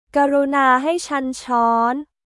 クルナー　ヘイ　チャン　チョン カップ/カー